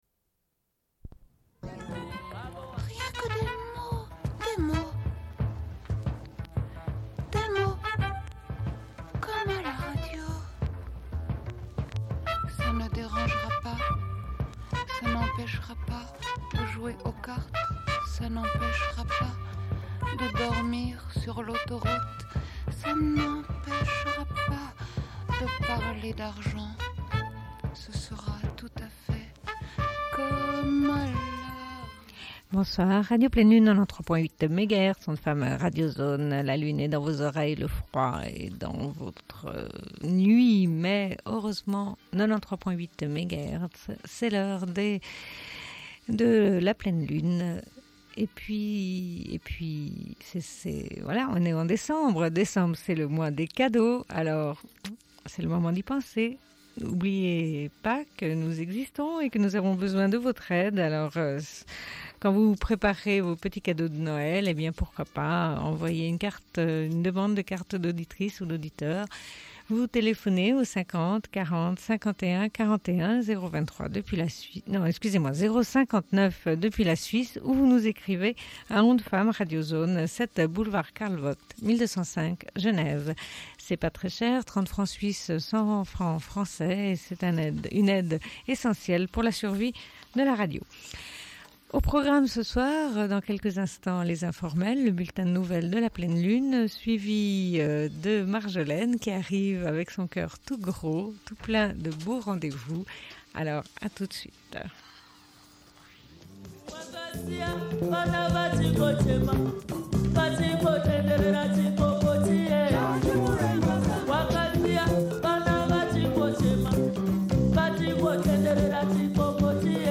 Bulletin d'information de Radio Pleine Lune du 06.12.1995 - Archives contestataires
Une cassette audio, face B